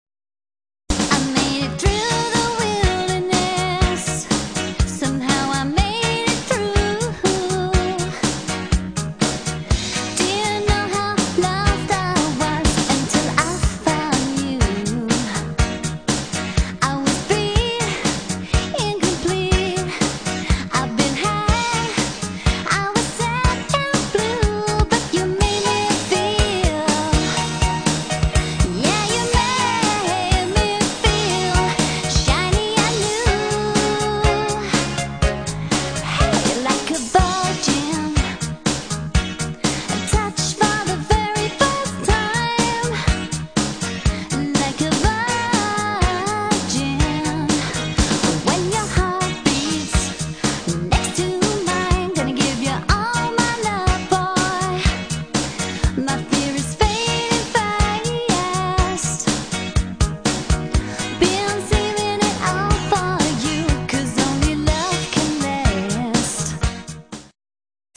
• Six-piece band
• Two female lead vocalists